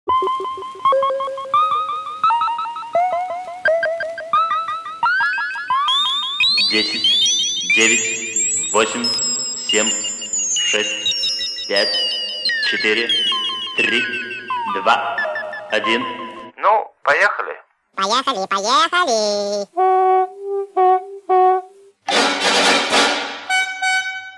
МузЗаставки